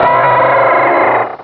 Cri de Linéon dans Pokémon Rubis et Saphir.